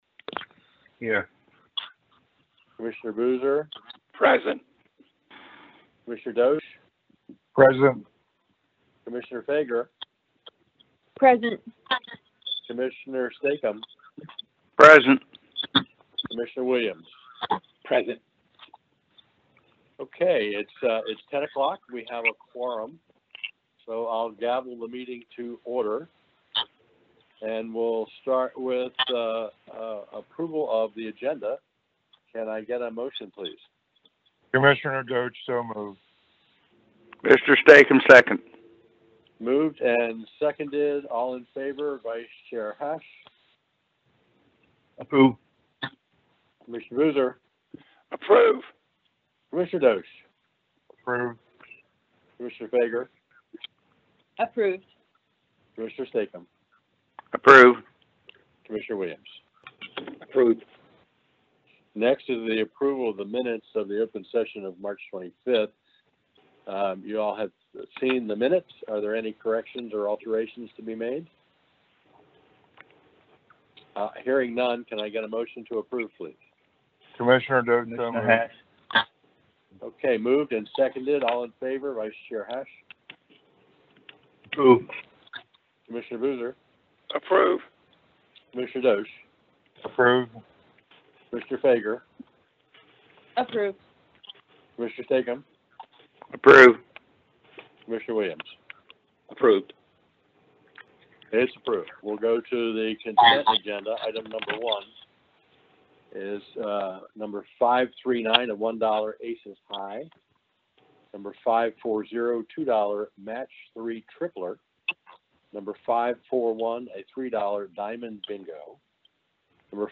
This Maryland Lottery and Gaming Commission meeting was held on Thursday, April 22, 2021, at 10:00 a.m. The meeting was held via teleconference.